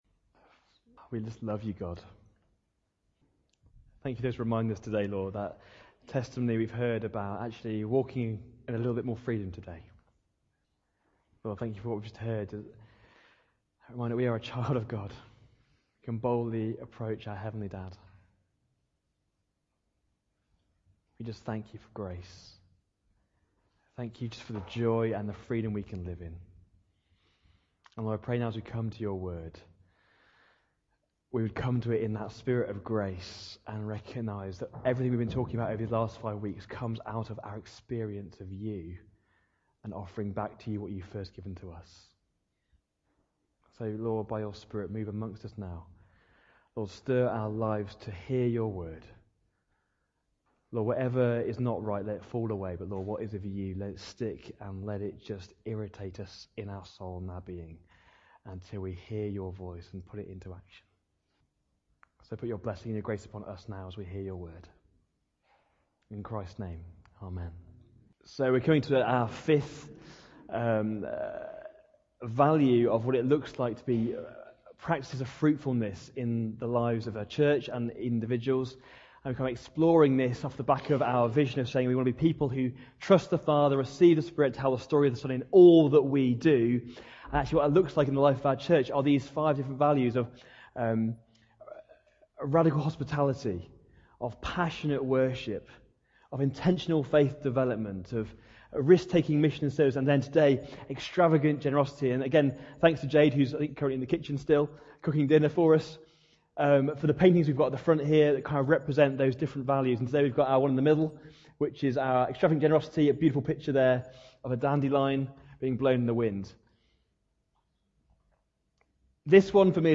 Nov 26, 2018 5 Practices for Fruitfulness: Extravagant Generosity MP3 SUBSCRIBE on iTunes(Podcast) Notes Discussion Sermons in this Series Generosity describes the Christian’s unselfish willingness to give in order to make a positive difference for the purposes of Christ.